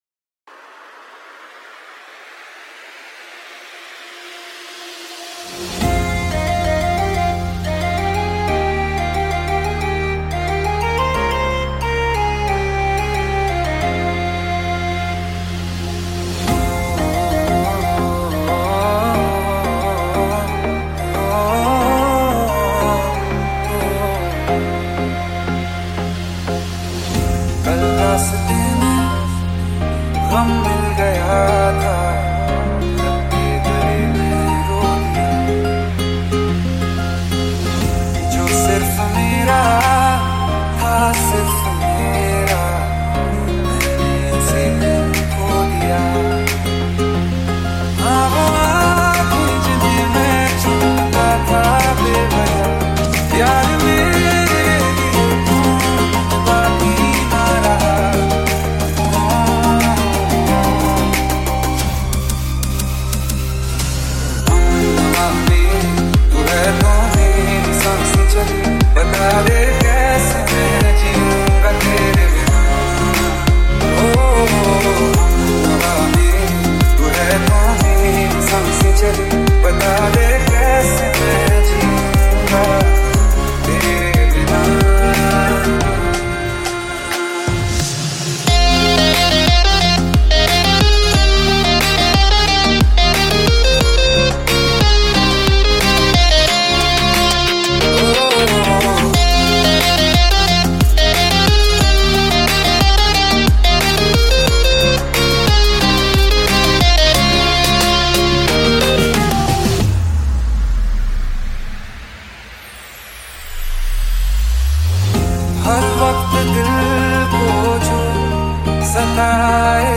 Genre - Electronic